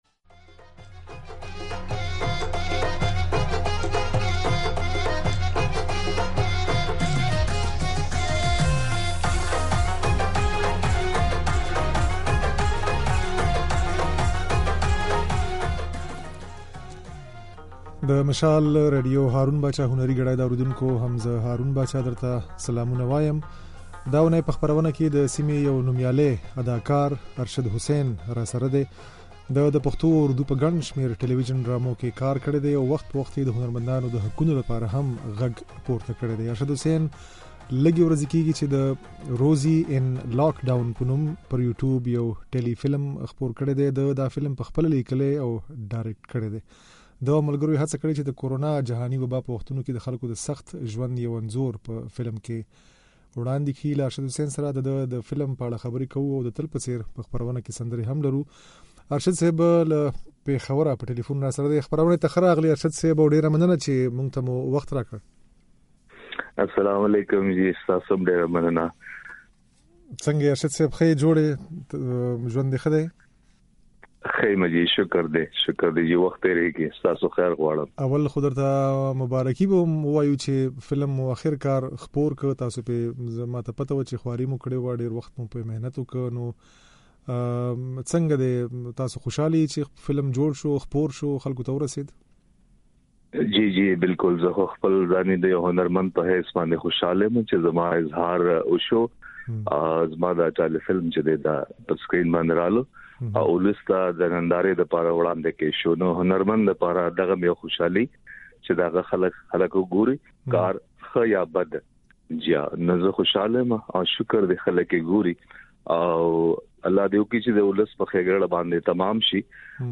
د دې اوونۍ د "هارون باچا هنري ګړۍ" خپرونې مېلمه د پښتو او اردو ډرامو يو نوميالی اداکار ارشد حسين دی.
نوموړی وايي، د فلم کيسه تر ډېره د کورونا جهاني وبا په وختونو کې د سيمې خلکو، په تېره هنرمندانو ته، له ورپېښو ستونزو څرخي. په خپرونه کې د ارشد حسين د فلم په اړه د خبرو ترڅنګ ځينې سندرې هم اورېدای شئ.